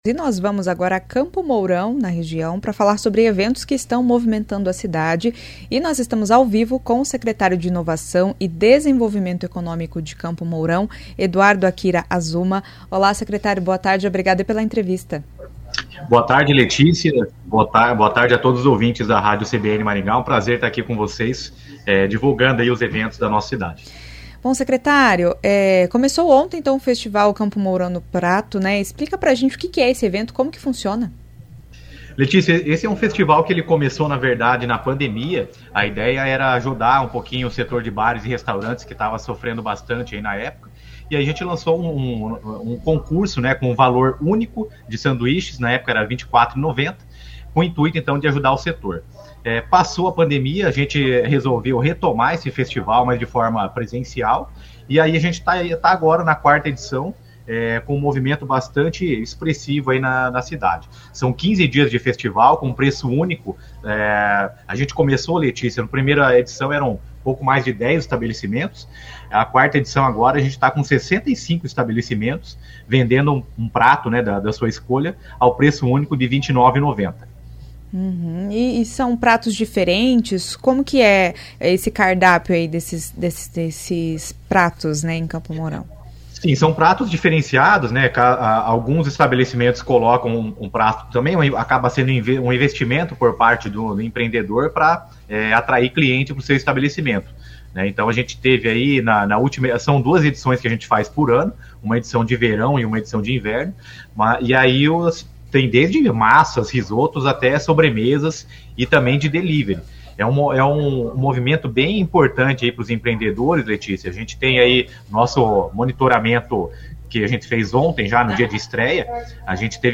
Ainda de acordo com o secretário, o 2º Agro Air Show começa na sexta-feira (26) com mais de 30 expositores de tecnologias para aviação e agricultura de precisão. Ouça a entrevista.